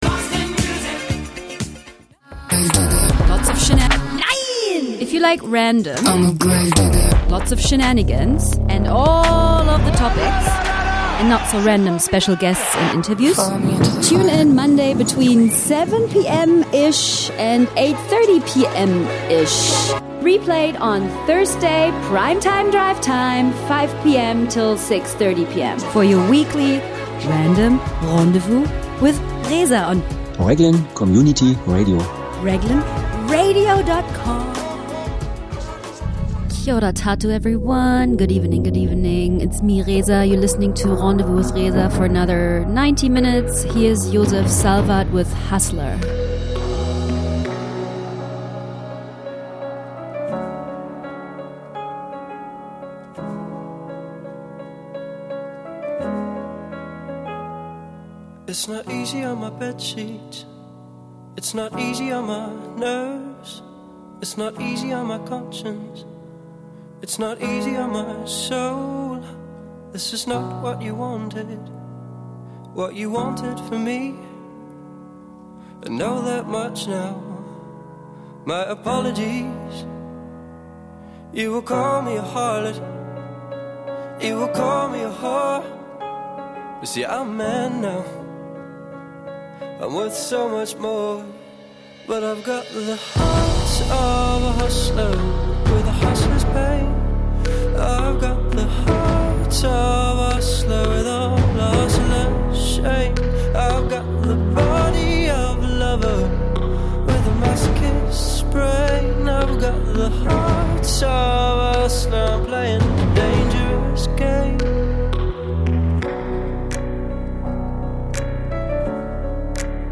Well, its random ok! A bit of rag and a bit of cheesy music for ya.